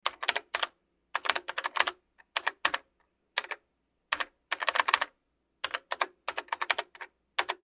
Vintage Keyboard 2; Early 1990's Personal Computer Keyboard; Sporadic Strokes, Close Perspective.